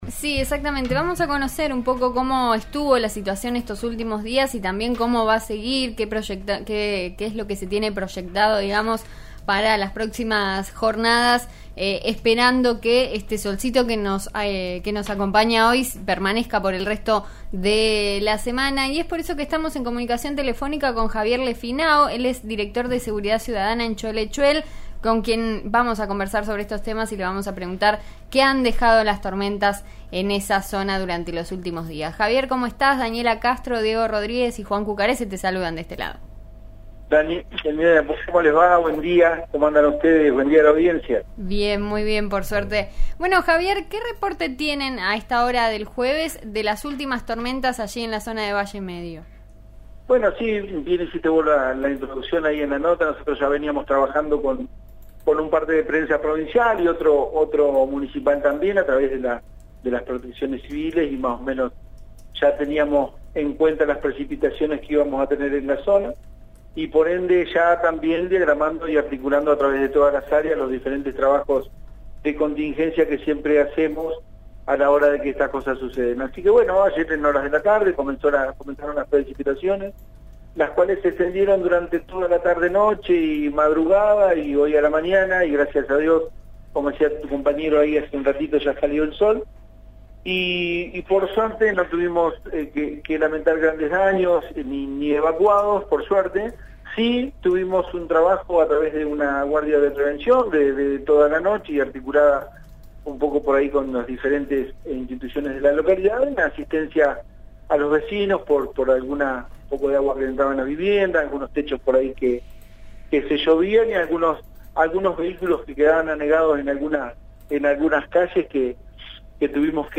En eso estamos de RN Radio (89.3) dialogó con Javier Lefinao, Director de Seguridad Ciudadana en Choele Choel, sobre los estragos de las tormentas de esta semana.